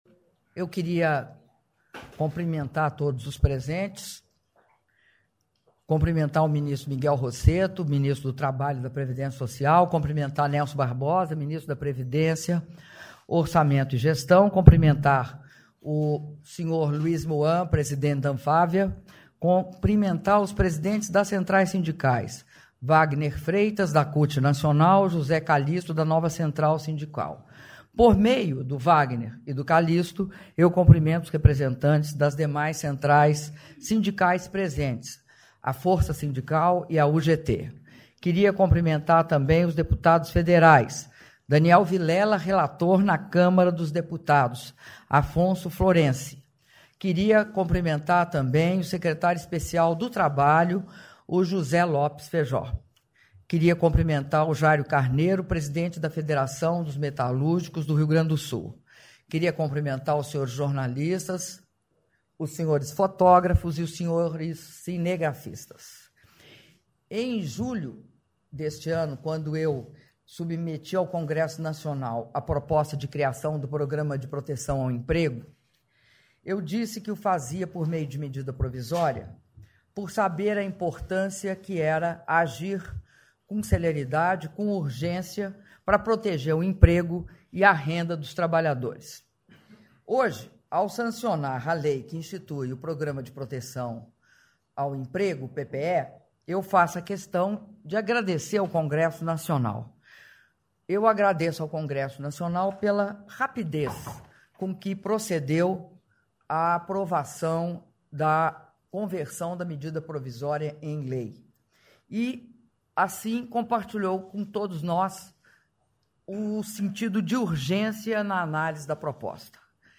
Áudio do discurso da Presidenta da República, Dilma Rousseff, na cerimônia de Sanção da Lei que institui o Programa de Proteção ao Emprego - Brasília/DF (06min15s)